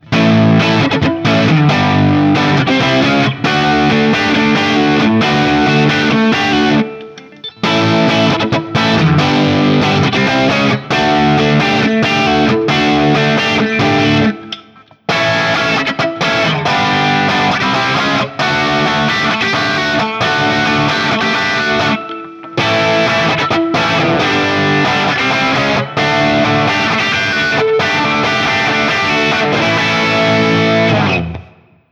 This 1994 Guild S100 can sound chimy, articulate, raunchy, and everything in-between.
Open Chords #2
JCM-800
A Barre Chords
More Barre Chords
As usual, for these recordings I used my Axe-FX II XL+ setup through the QSC K12 speaker recorded direct via USB to my Macbook Pro.
For each recording (except the last two) I cycle through the neck pickup, both pickups (in phase then out of phase), and finally the bridge pickup.